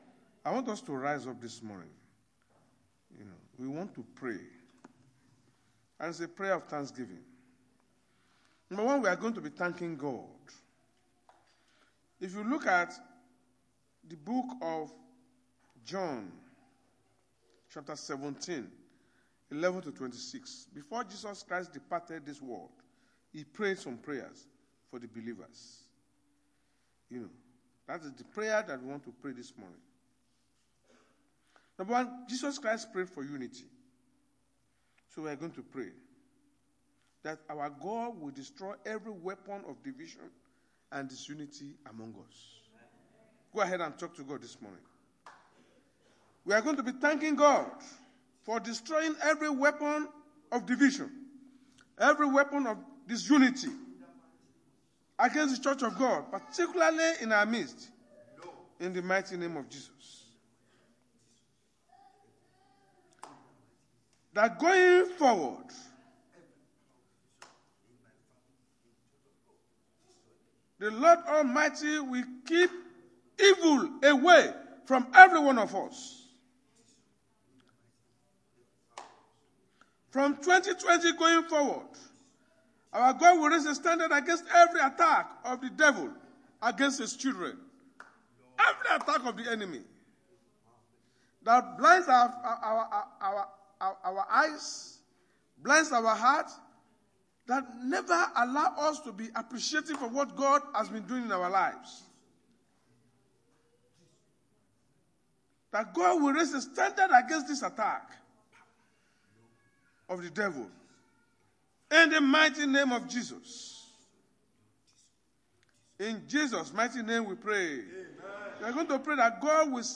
Redeemed Christian Church of God-(House Of Glory) sunday sermons.
Service Type: Sunday Church Service